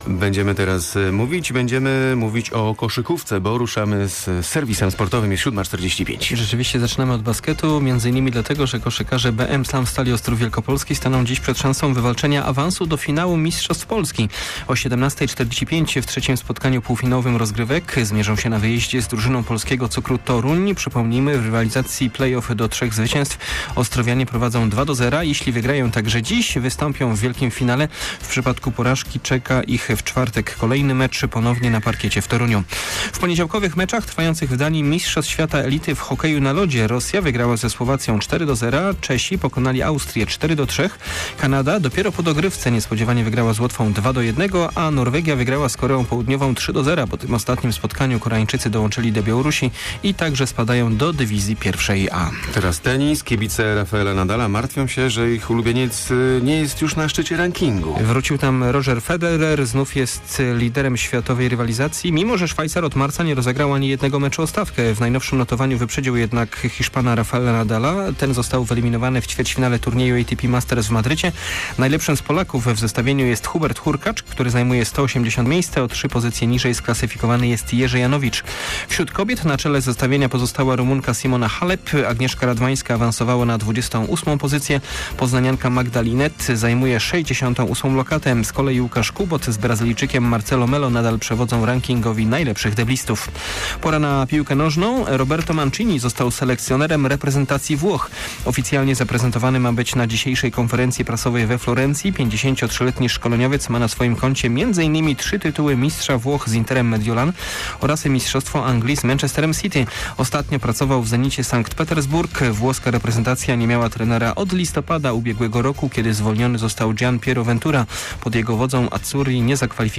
15.05 serwis sportowy godz. 7:45